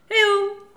Les sons ont été découpés en morceaux exploitables. 2017-04-10 17:58:57 +02:00 132 KiB Raw History